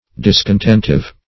Search Result for " discontentive" : The Collaborative International Dictionary of English v.0.48: Discontentive \Dis`con*tent"ive\, a. Relating or tending to discontent.
discontentive.mp3